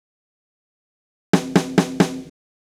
Drumset Fill 02.wav